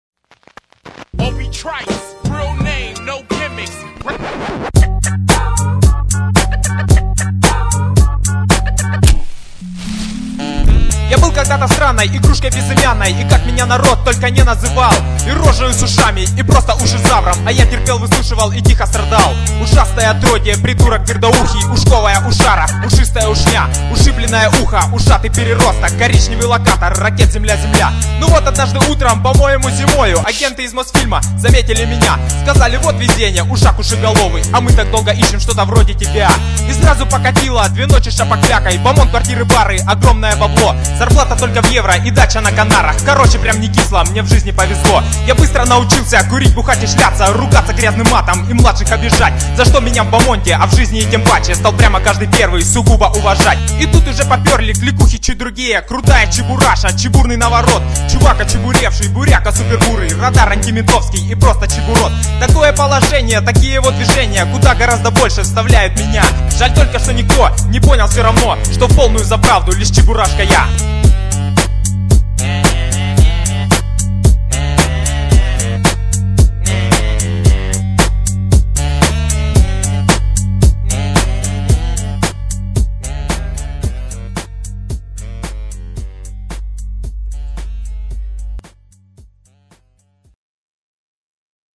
реп про чибурашку